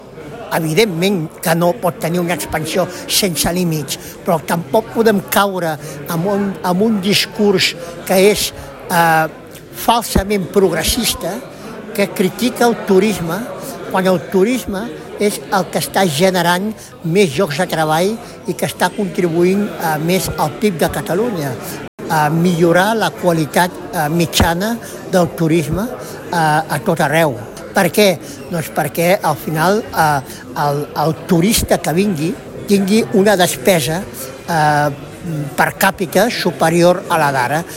Dinar d’empresaris de la zona amb Jaume Giró, el número 11 de la llista de Junts+ Puigdemont per Catalunya.